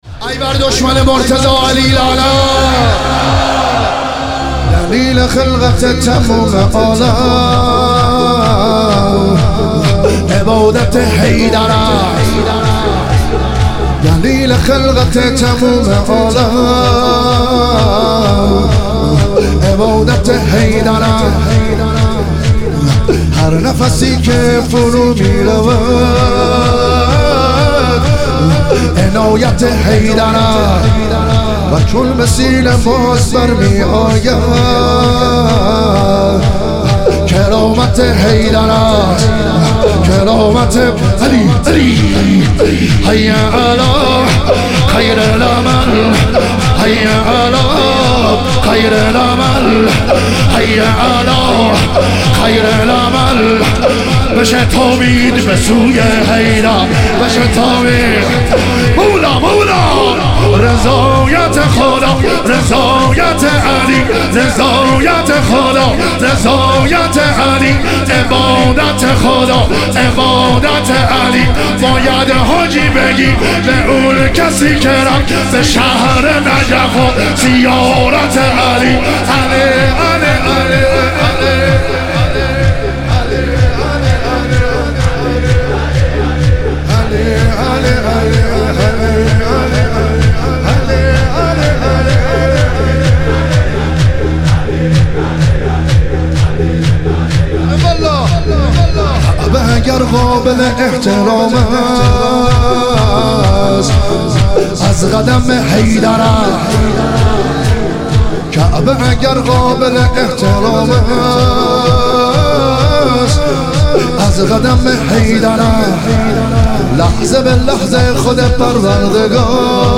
تخریب بقیع - شور